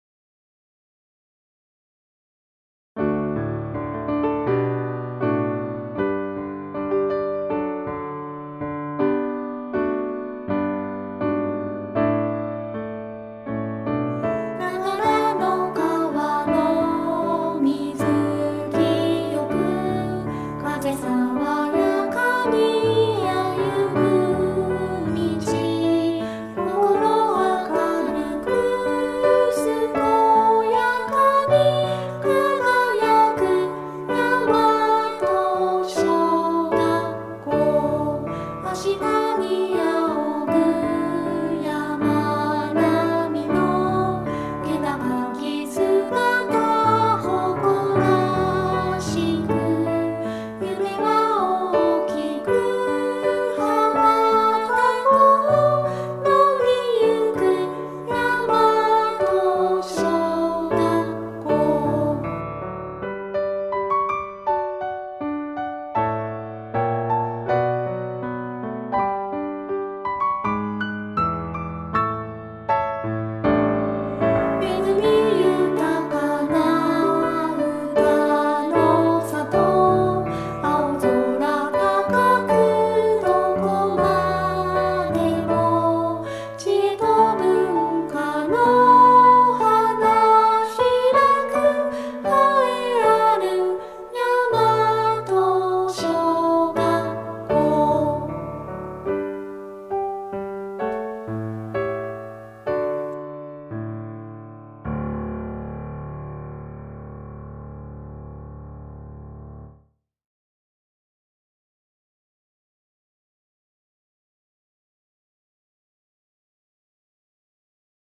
大和小学校校歌（合唱） 試聴用（mp3・2,769KB）
yamatosho_kouka_gasshou.mp3